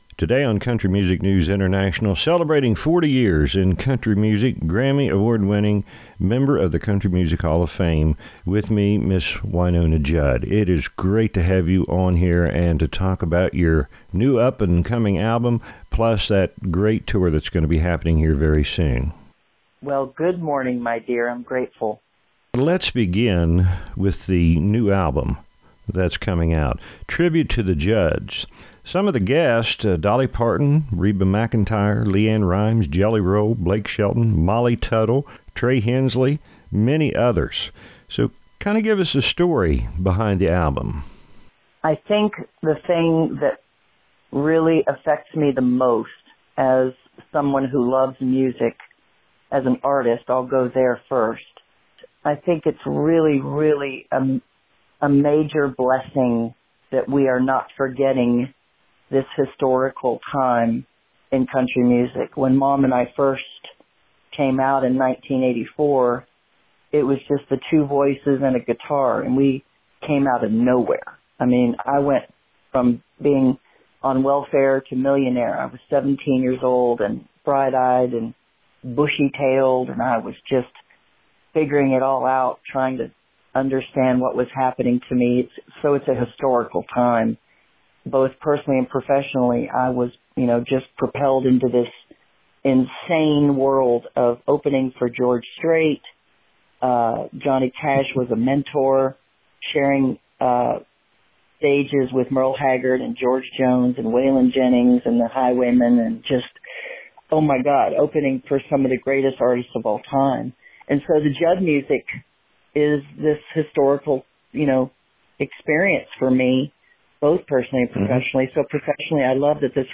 Wynonna Interview